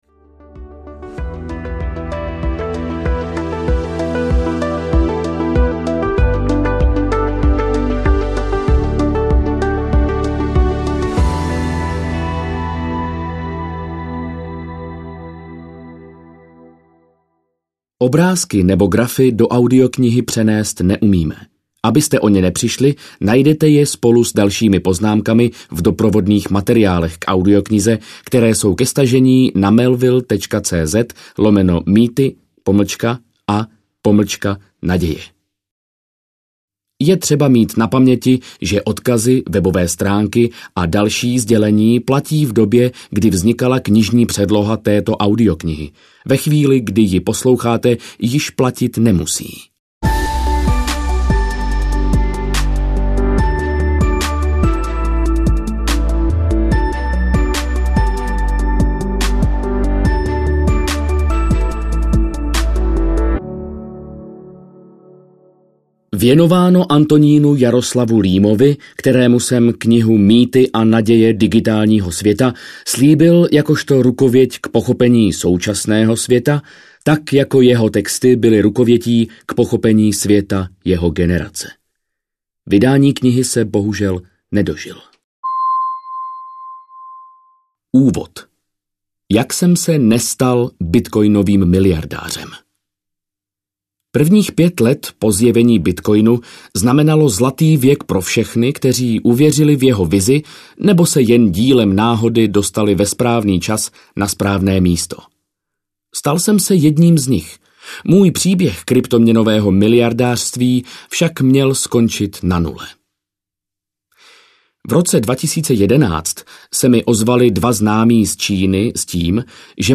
Mýty a naděje digitálního světa audiokniha
Ukázka z knihy